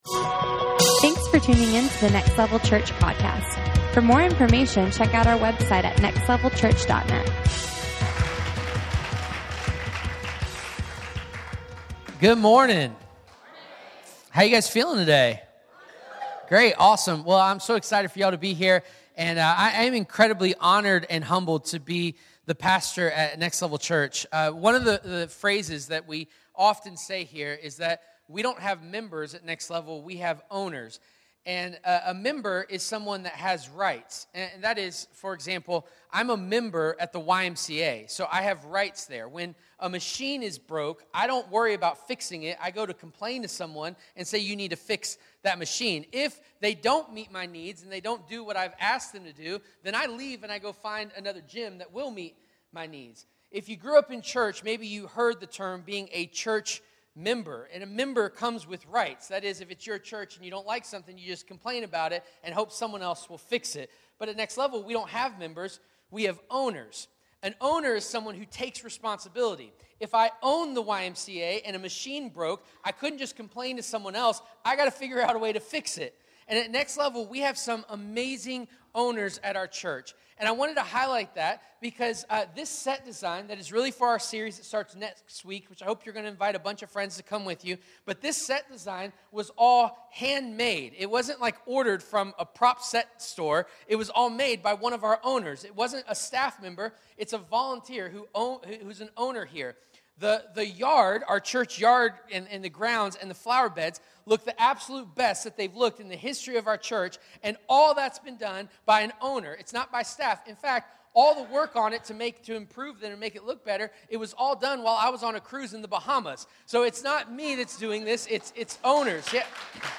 Q&A – 11:15AM Service